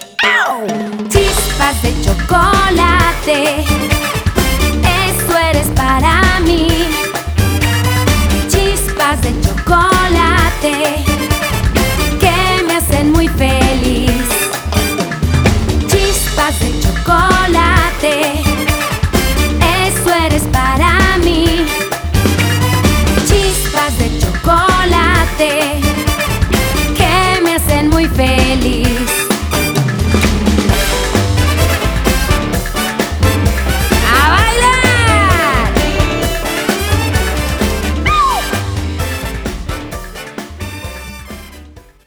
In 2009, she released another new children's album.